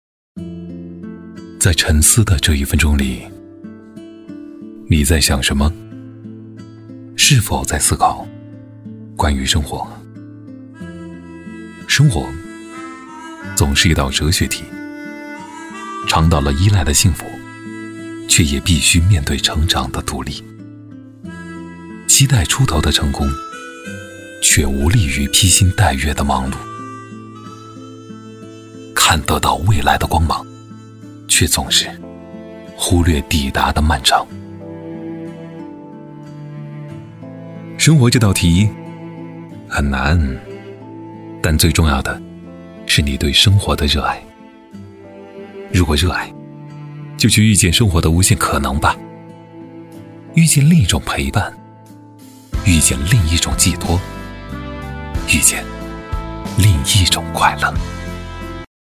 男139-微电影旁白【生活 沉稳自然讲述】
男139-磁性沉稳 质感磁性
男139-微电影旁白【生活 沉稳自然讲述】.mp3